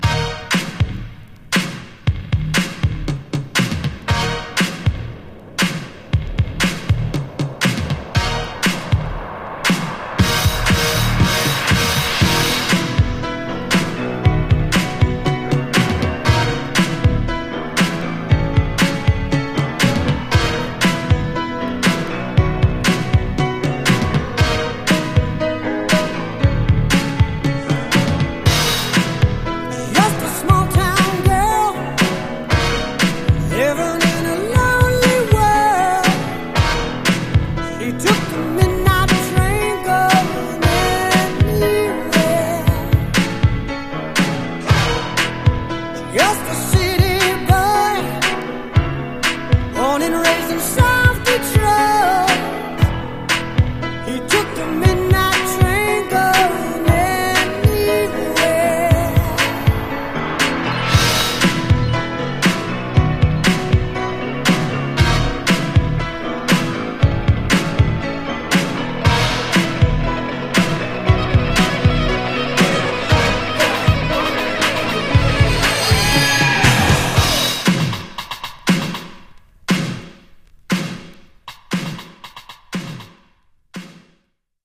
120 bpm